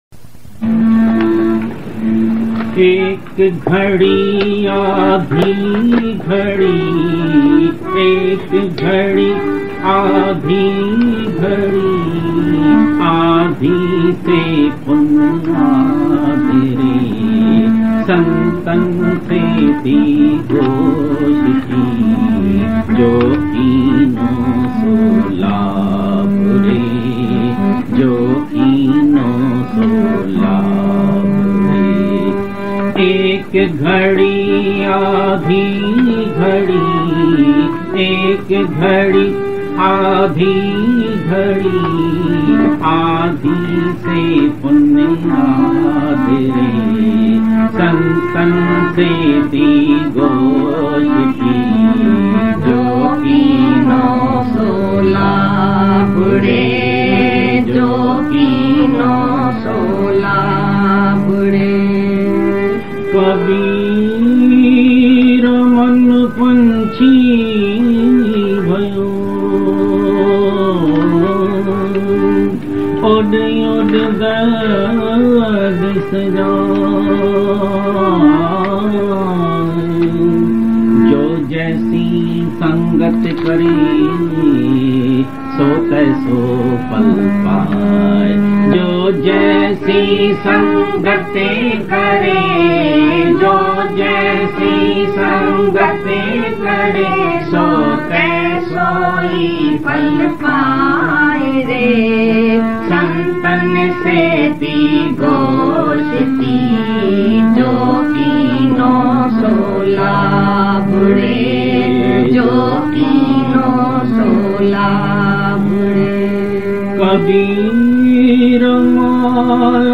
Ek-Ghadi-Aadhi-Ghadi-Aadhi-te-Punah-Aadh-re-Bhajan-.mp3